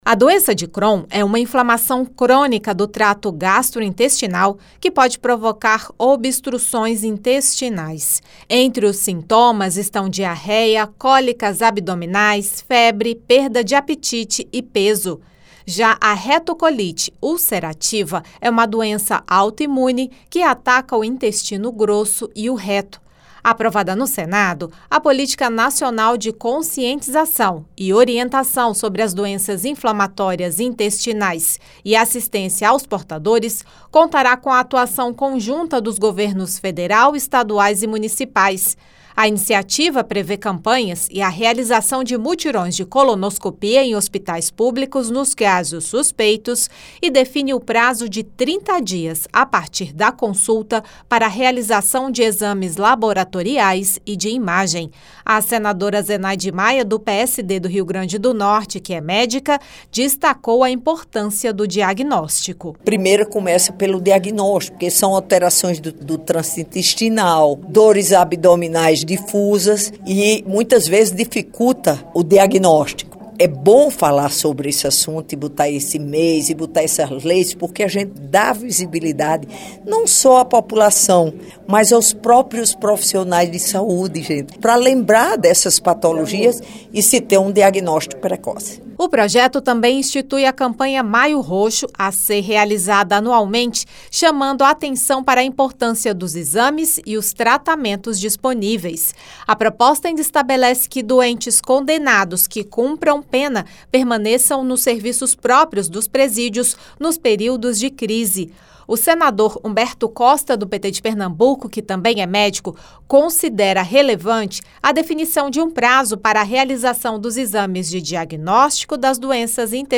A senadora Zenaide Maia (PSD-RN) destacou a importância de se identificar a doença para o tratamento ser iniciado, já que as duas doenças não têm cura. O também médico, senador Humberto Costa (PT-PE), destacou que exames no tempo certo podem dar qualidade de vida para esses pacientes.